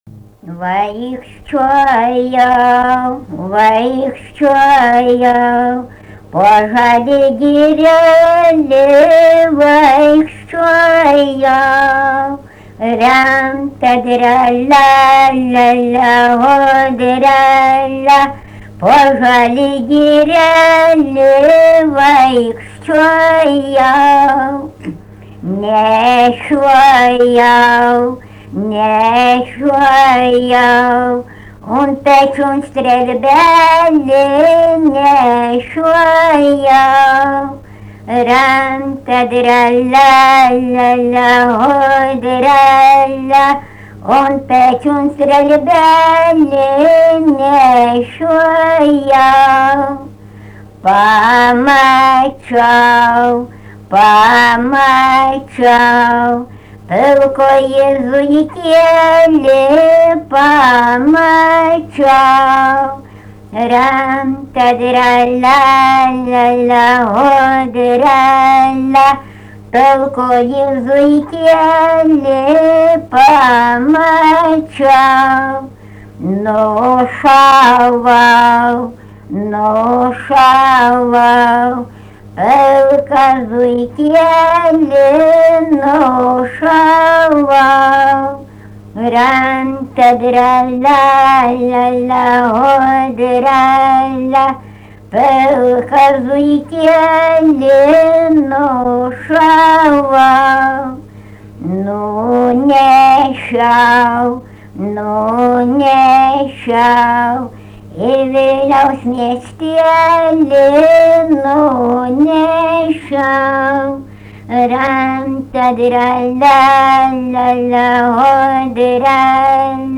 daina
Barvydžiai
vokalinis